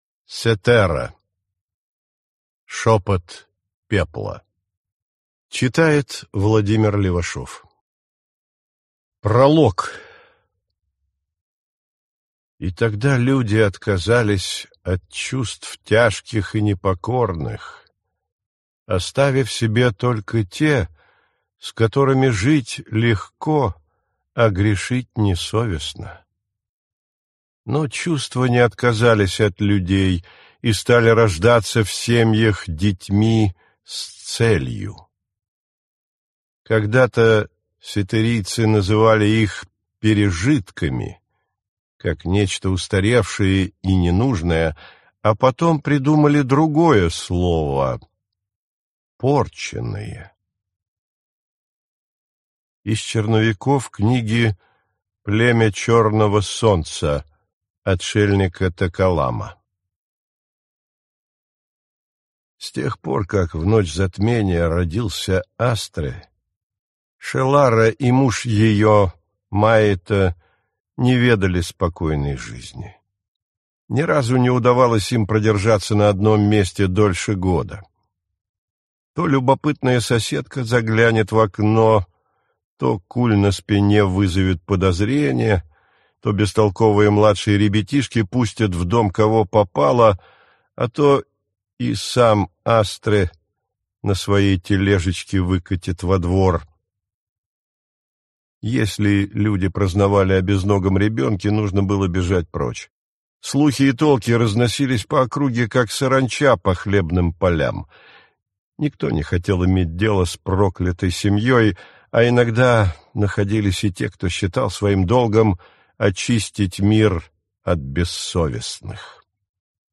Аудиокнига Шепот пепла | Библиотека аудиокниг
Прослушать и бесплатно скачать фрагмент аудиокниги